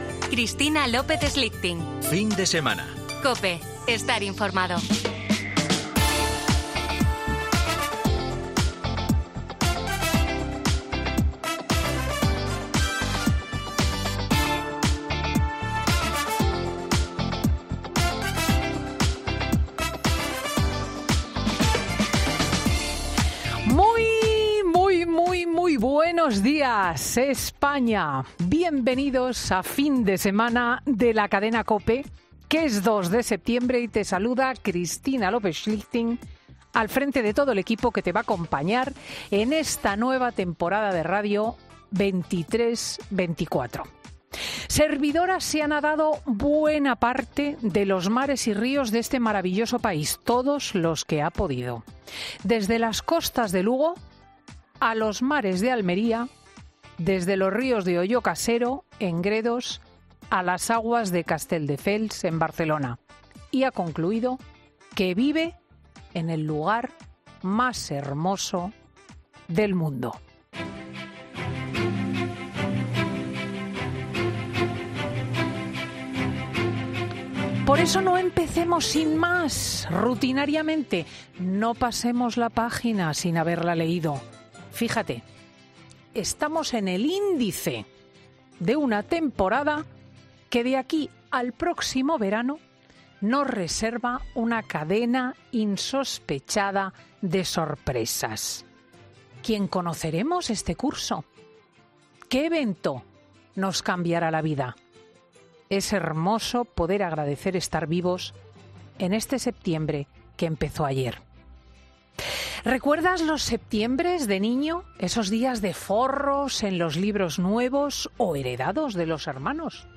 Escucha el monólogo de Cristina L. Schlichting en 'Fin de Semana'